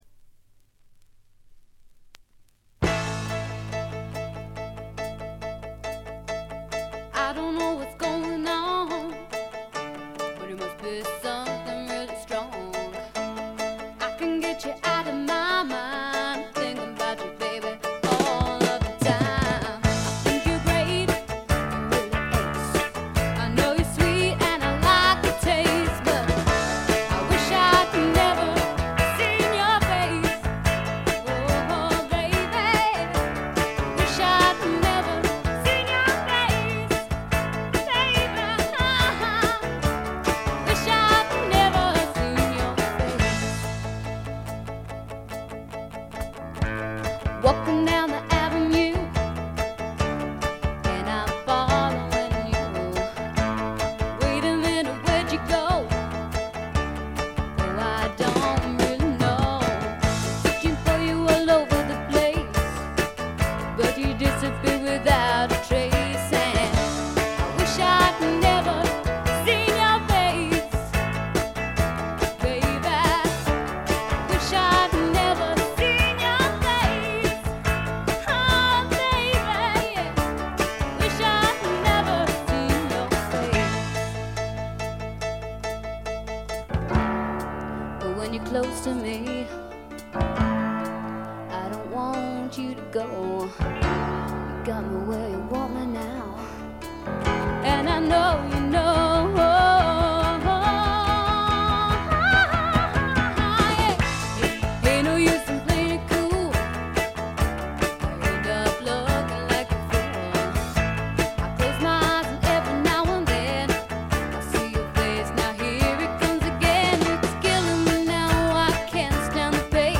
イングランドの小さなレーベルに残された女性シンガーの好盤です。
試聴曲は現品からの取り込み音源です。